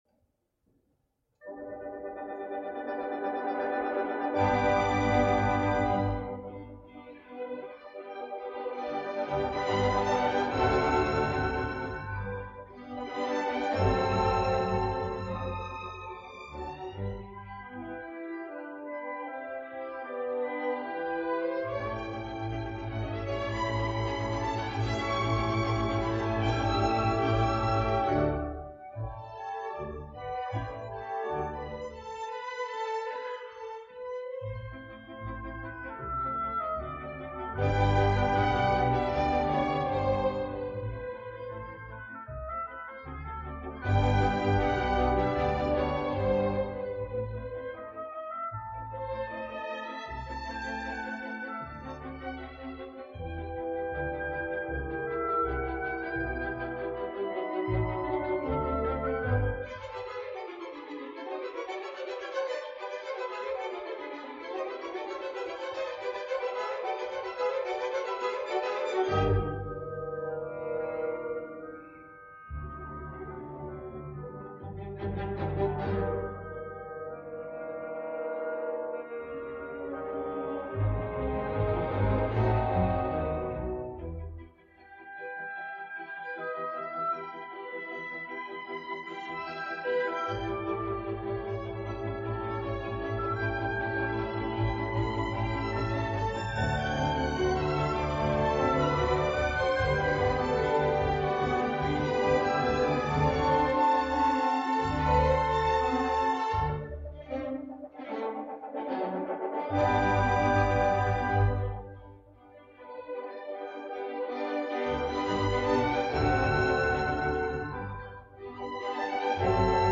La soprano sueca
alhora que hi ha un vibrato i una veu de timbre proper a l’acer